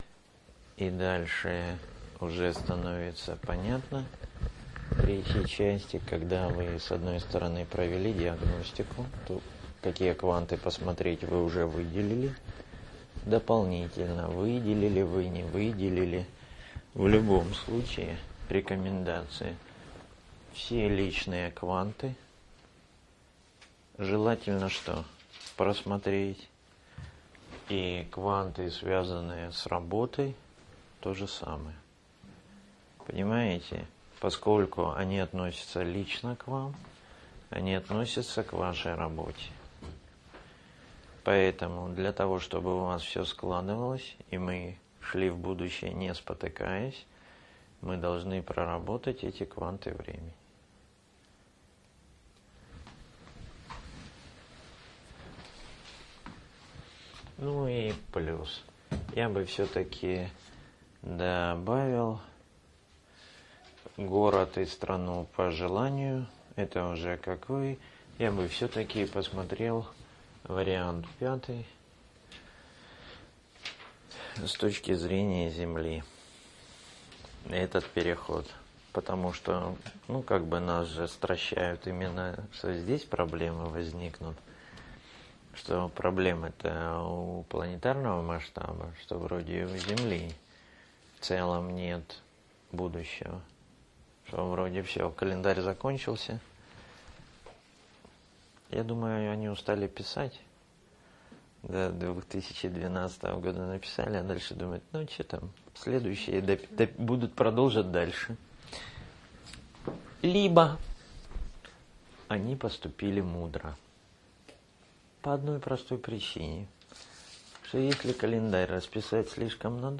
Лекции Семинар